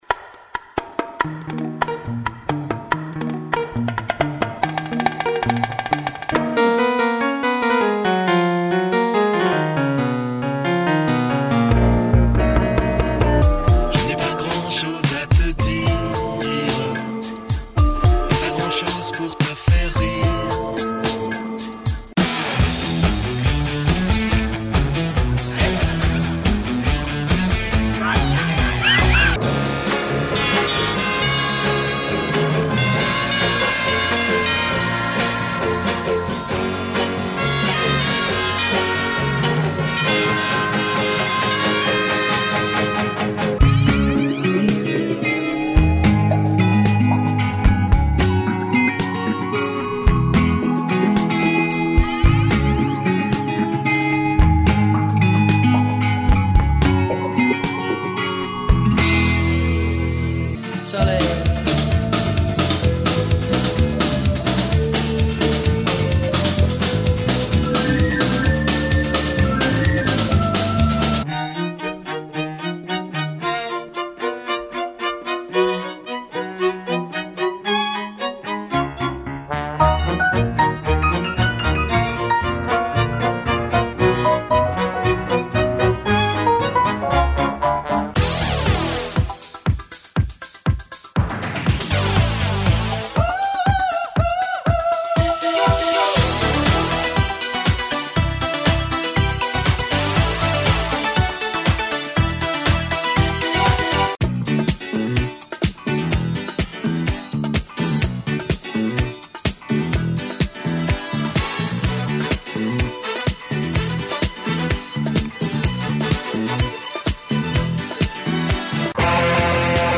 10 chansons françaises sur 10